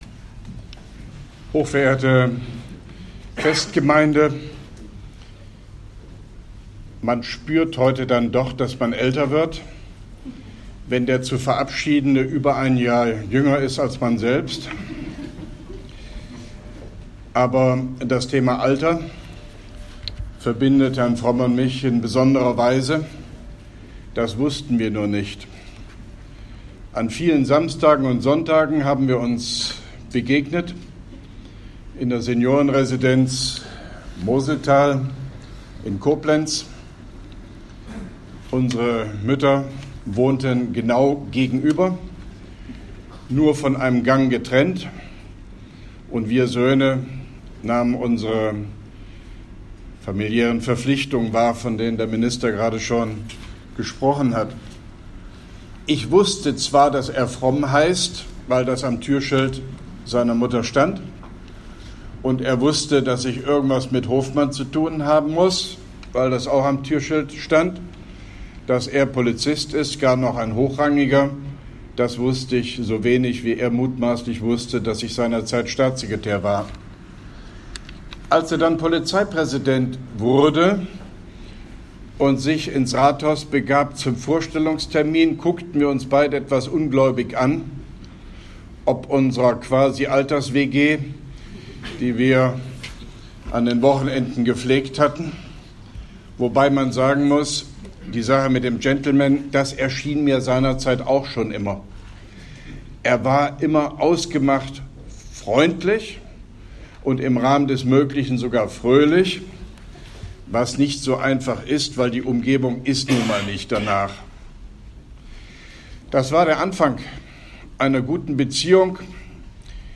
Grußwort von OB Hofmann-Göttig bei der Verabschiedung des Polizeipräsidenten Wolfgang Fromm und Amtseinführung des neuen Polizeipräsidenten Karlheinz Maron, Koblenz 26.09.2017